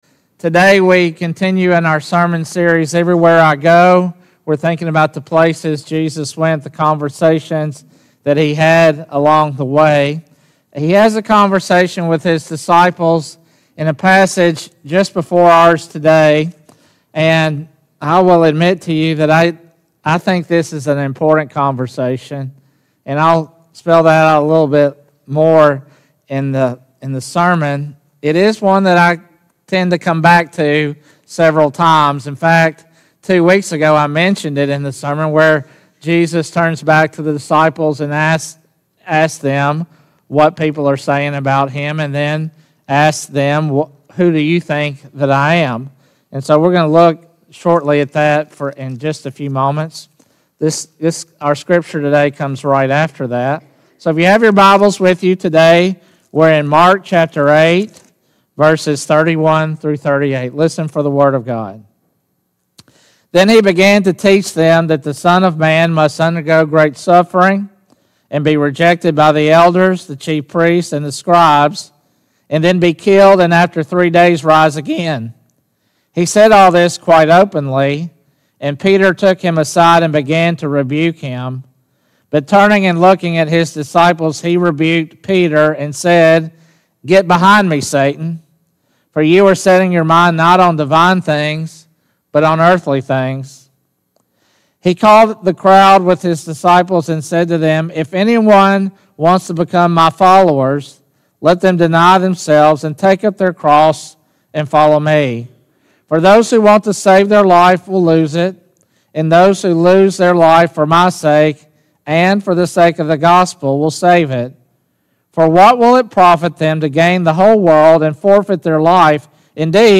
Sermons | First United Methodist Church